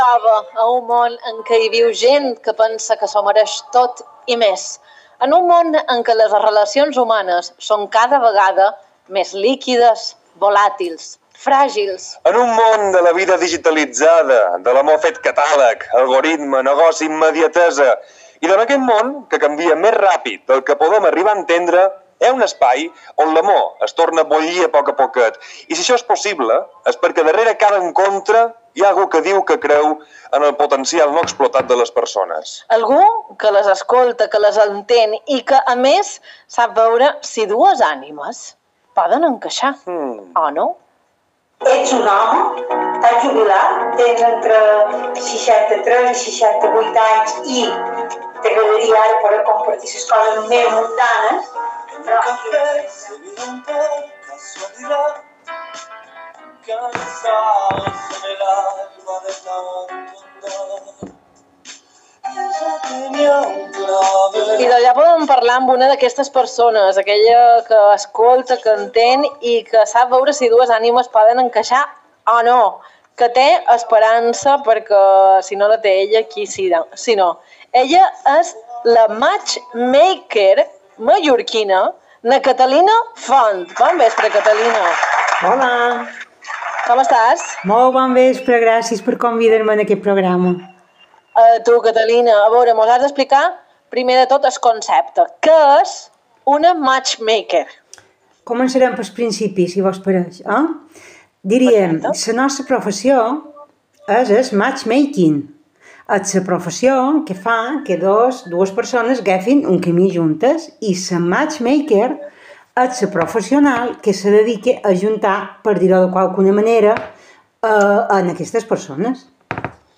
Entrevista Radio